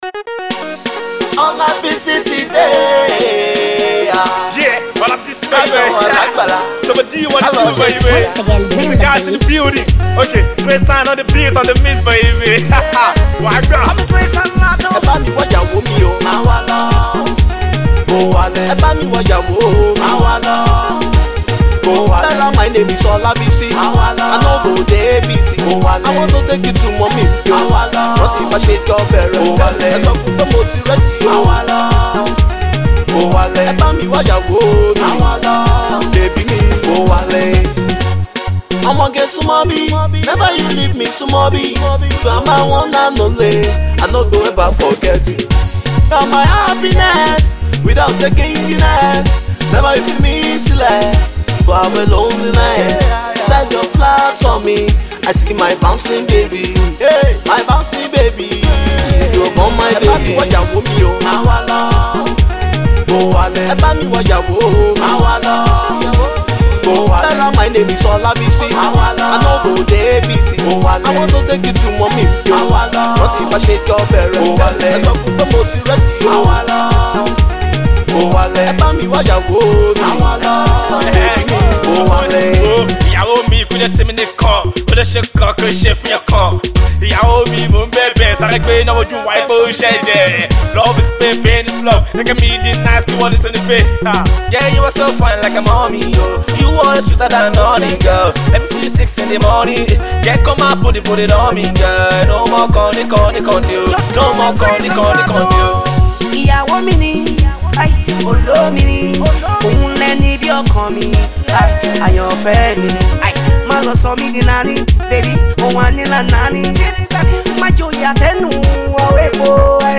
Fast paced Pop tune